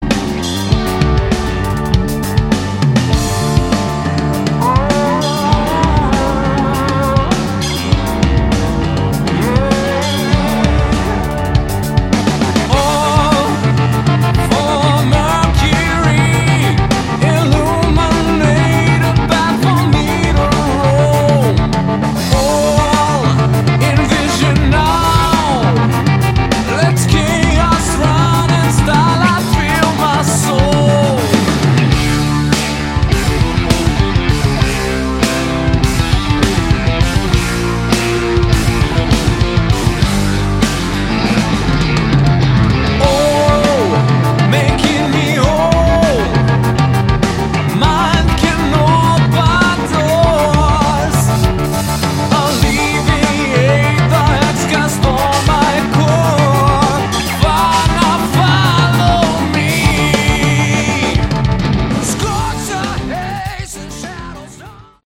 Category: Melodic Metal
vocals, guitars
vocals, keyboards
bass
drums